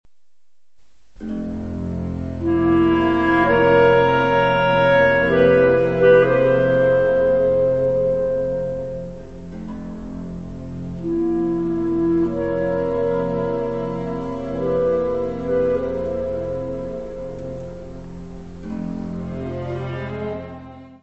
Music Category/Genre:  Classical Music